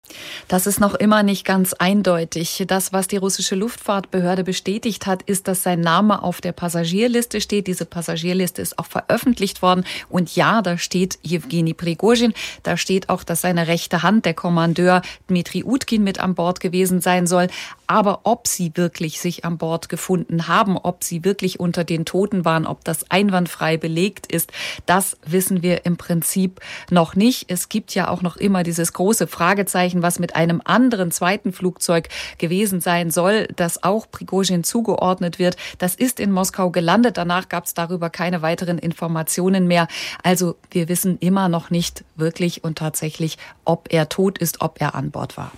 aus Moskau zu der Frage, ob der Tod von Prigoschin inzwischen bestätigt ist und ob er überhaupt wirklich an Bord war: